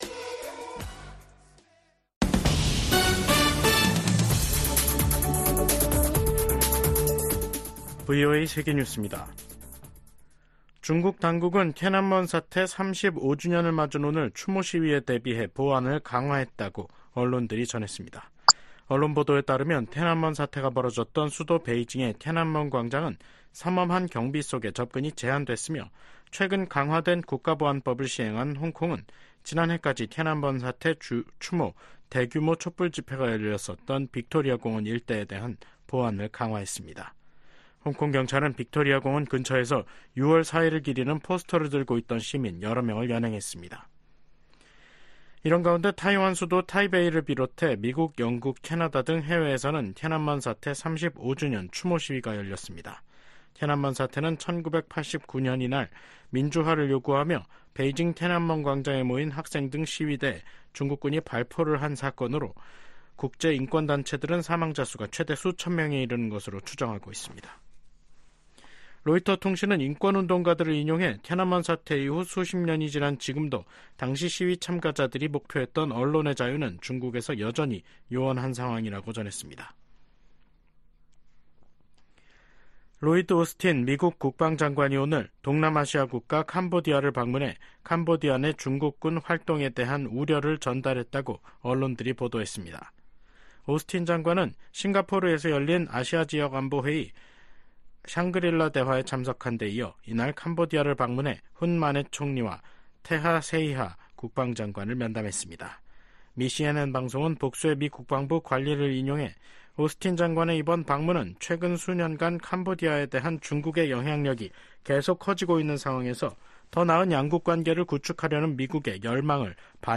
VOA 한국어 간판 뉴스 프로그램 '뉴스 투데이', 2024년 6월 4일 3부 방송입니다. 한국 정부는 9.19 군사합의 효력을 전면 정지시킴으로써 대북 확성기 사용과 함께 군사분계선(MDL)일대 군사훈련 재개가 가능하도록 했습니다. 미국의 북한 전문가들은 군사합의 효력 정지로 한국이 대비 태세를 강화할 수 있게 됐으나, 남북 간 군사적 충돌 위험성 또한 높아졌다고 진단했습니다.